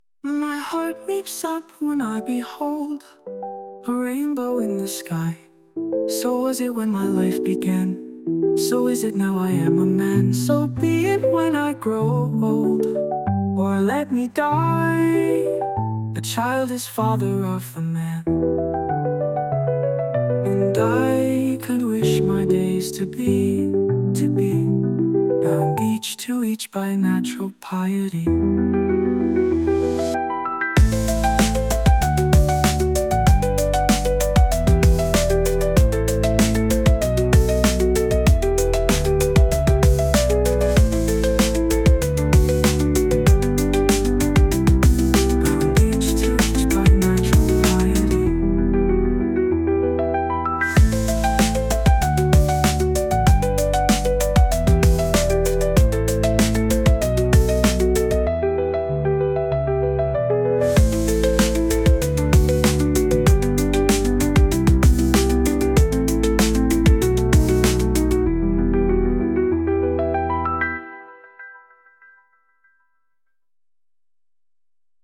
５月１８日　AI による作曲・演奏
歌詞を与えれば、作曲・演奏・ボーカル　すべてやってくれます